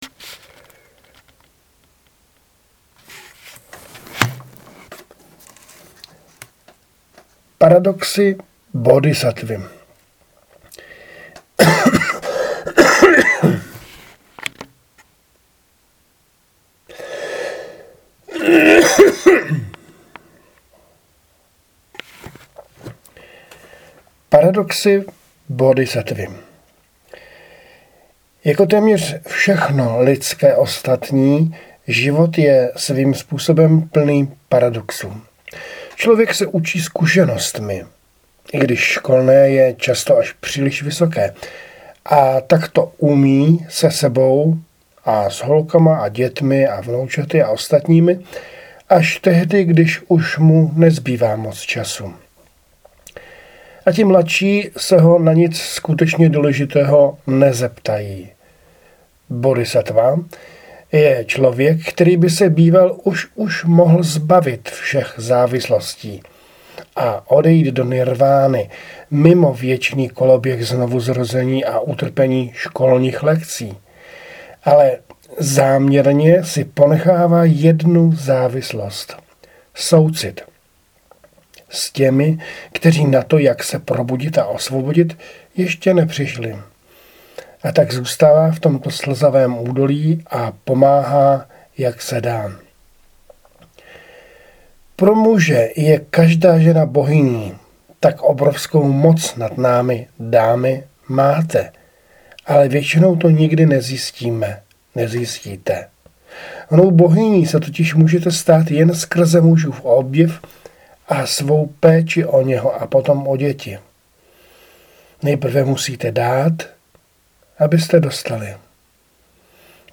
Namluvené fejetony (podcast)